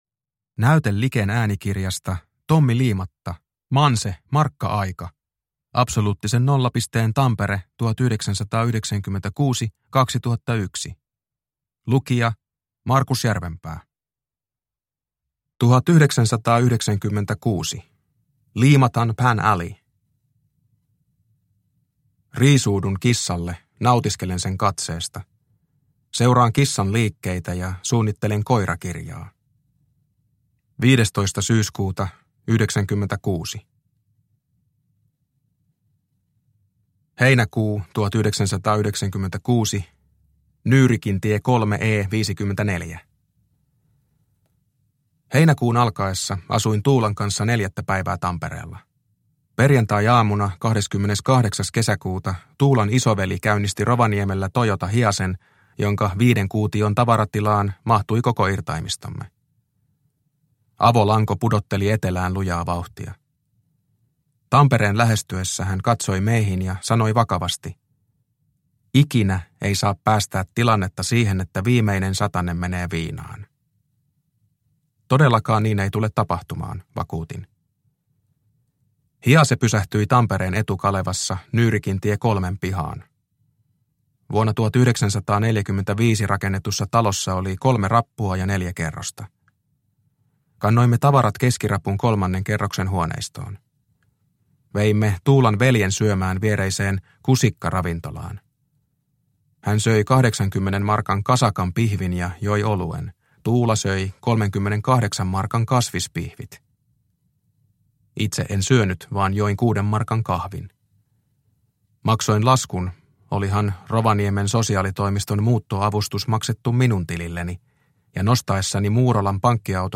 Manse – Markka-aika – Ljudbok – Laddas ner